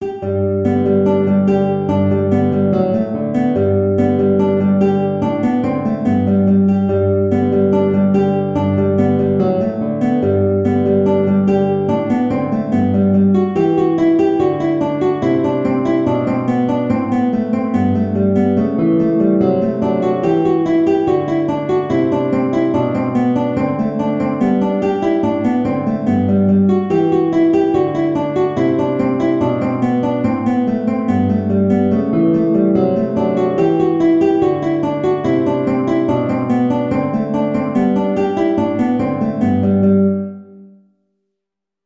2/2 (View more 2/2 Music)
Guitar  (View more Intermediate Guitar Music)